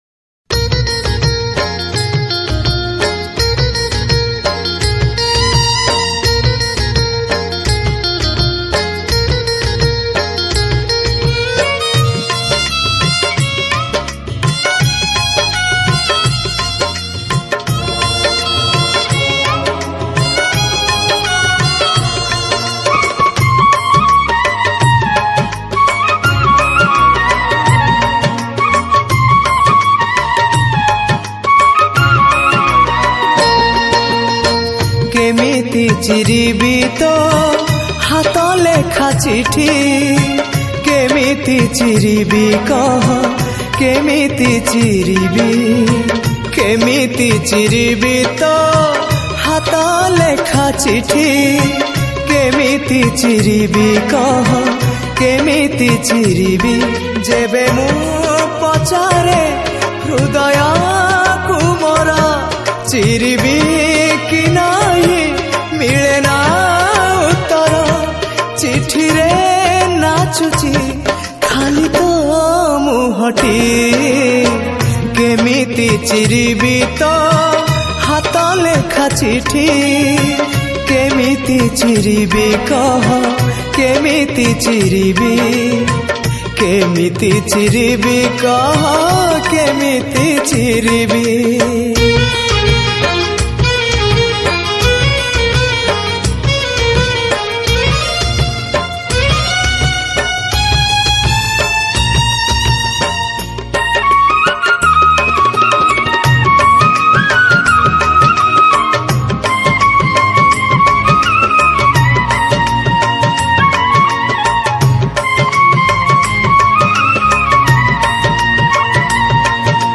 Recorded At: Sun Studio